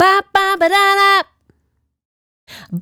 Bop Babba 085-G.wav